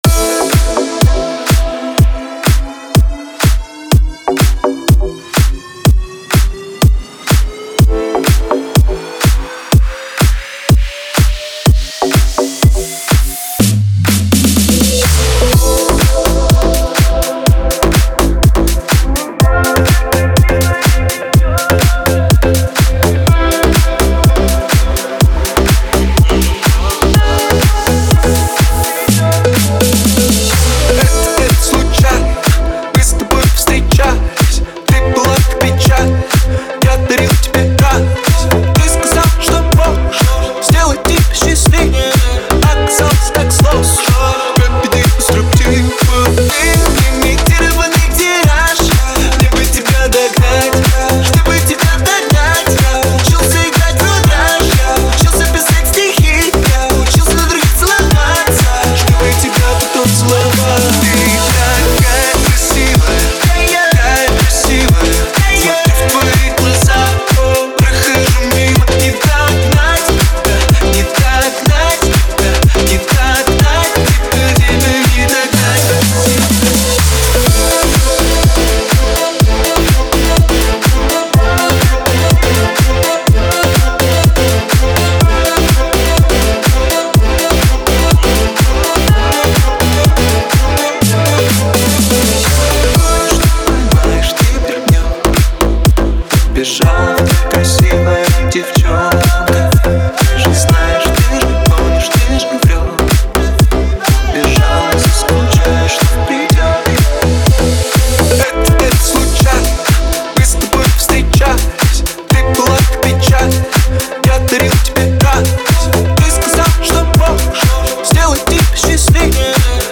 Трек размещён в разделе Клубная музыка | Ремиксы.